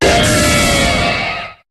Cri de Giratina dans Pokémon HOME.